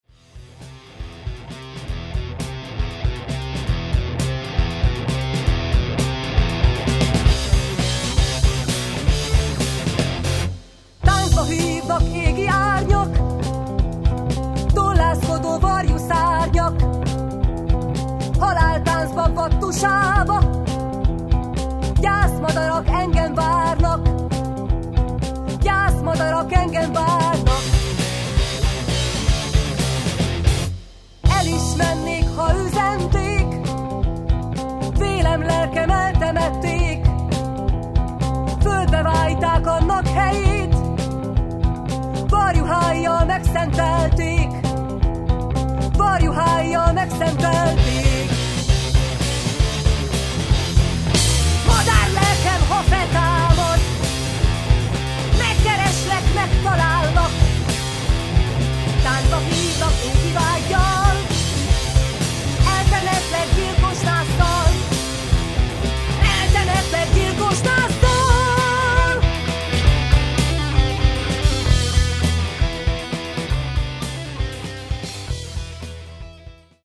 basszusgitár
gitár
dobok